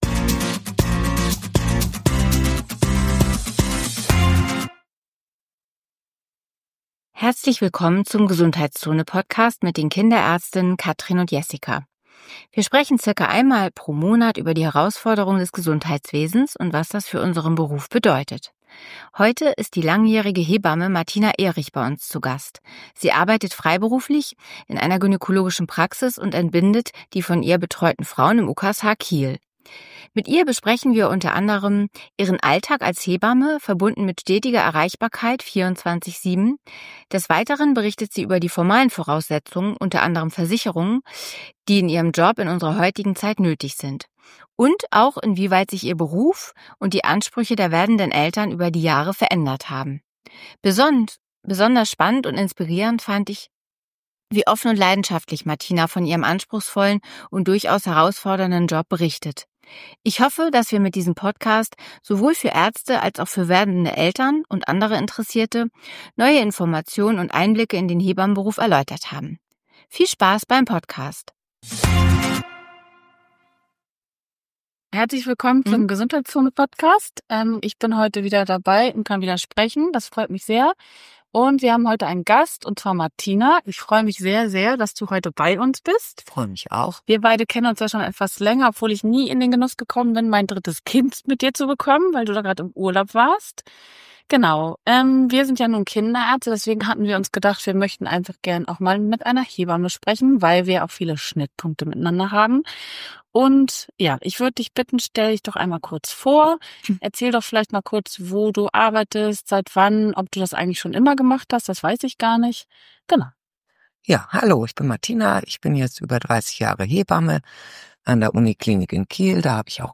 G#11 Hebamme 24/7 ~ GESUNDHEITSZONE // zwei Kinderärzte über Probleme und Lösungen in ihrem Job Podcast